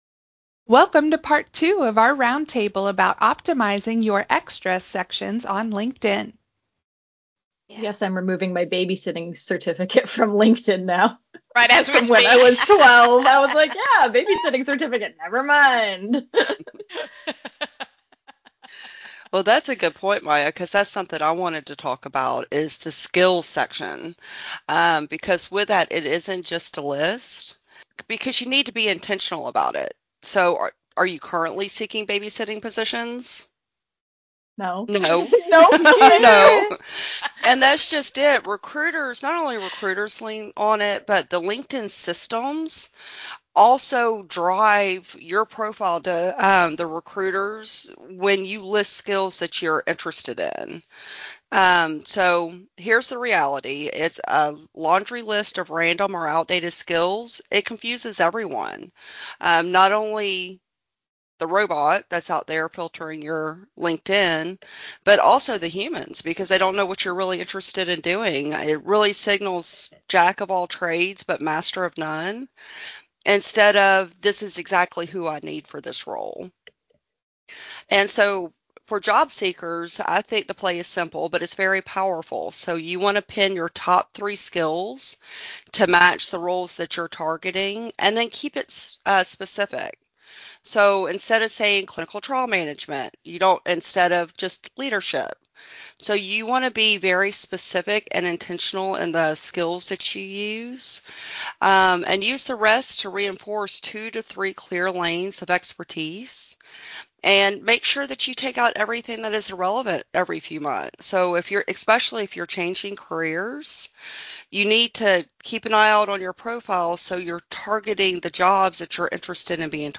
Roundtable: LinkedIn Skills and Publication Sections - craresources